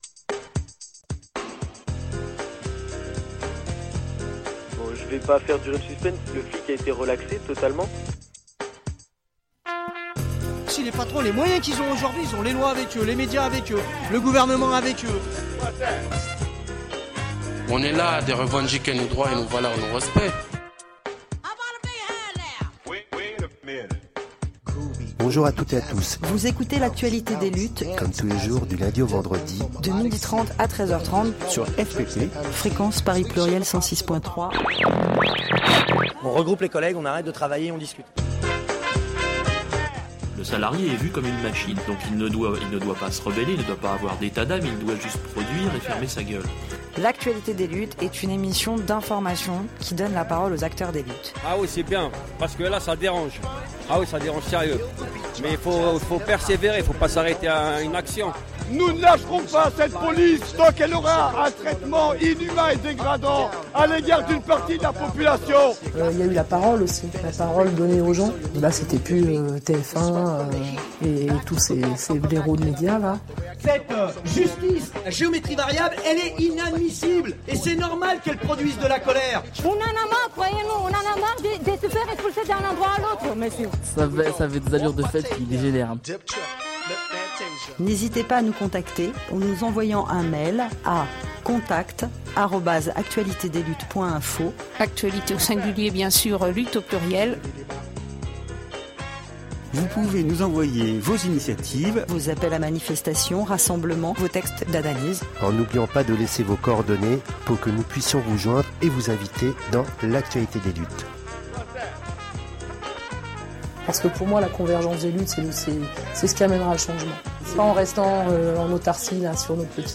nous vous diffusons l’enregistrement des prises de paroles de la réunion publique organisée le 04 février 2026 à la Fléche d’Or